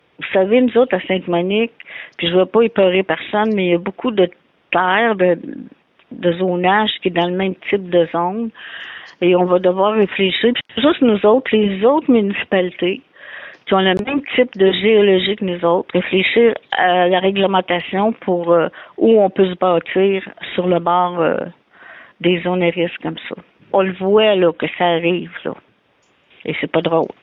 La mairesse de Sainte-Monique a rappelé l’importance de se pencher sur ce problème.